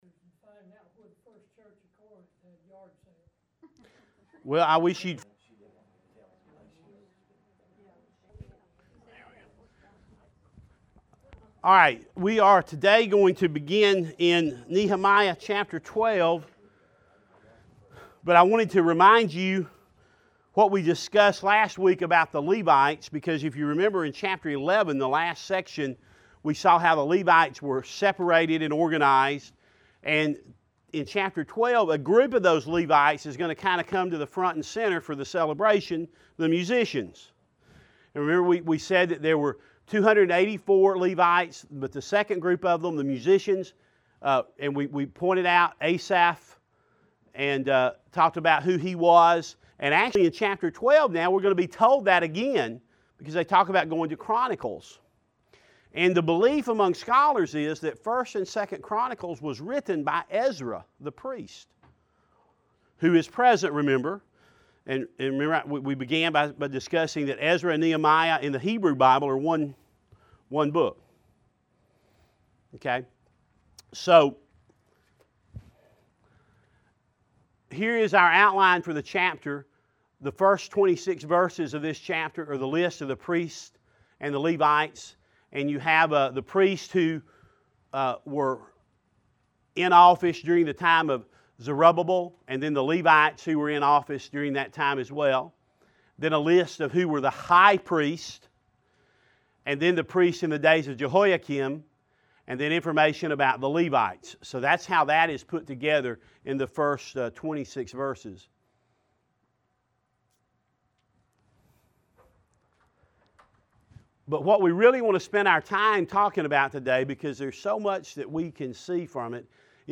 Bible Studies
NehemiahLesson12.mp3